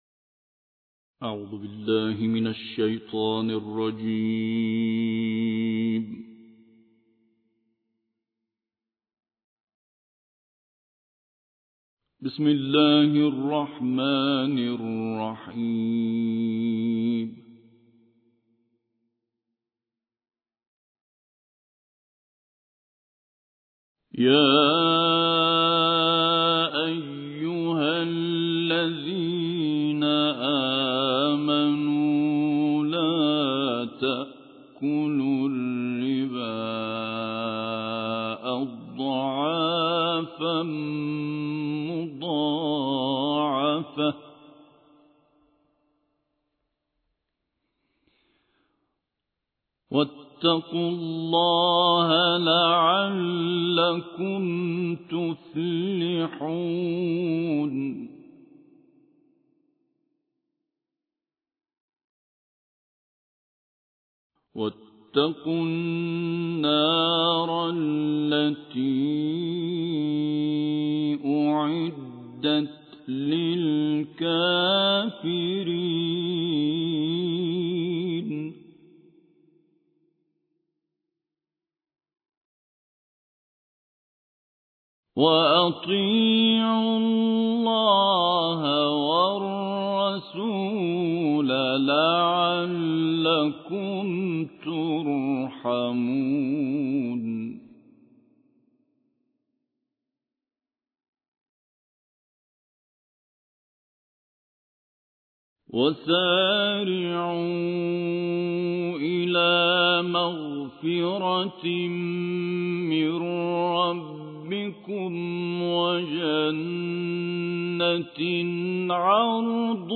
دانلود قرائت سوره آل عمران آیات 130 تا 148 - استاد سعید طوسی